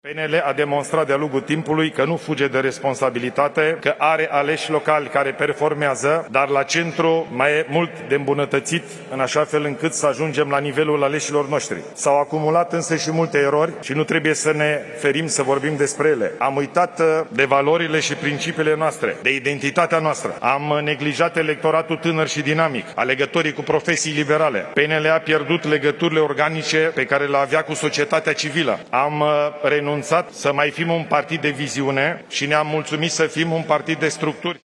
*Ilie Bolojan, discurs în cadrul Congresului Extraordinar al Partidului Național Liberal – 12 iulie 2025*